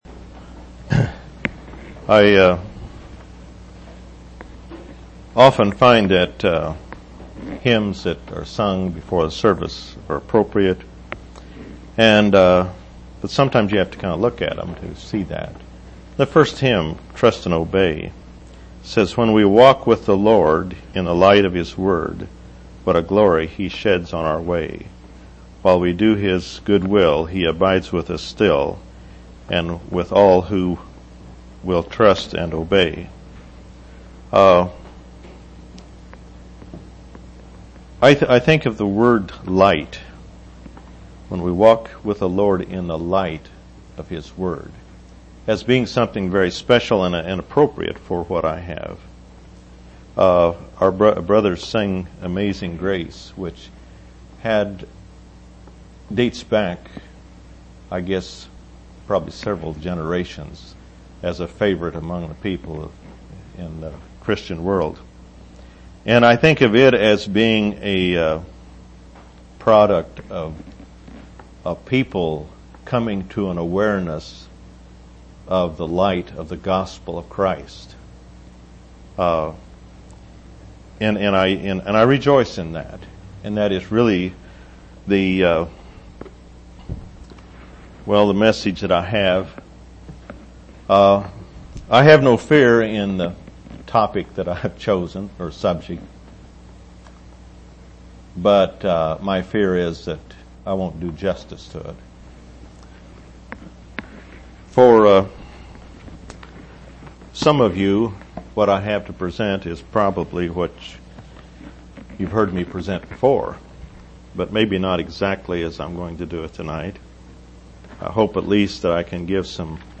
9/26/1999 Location: East Independence Local Event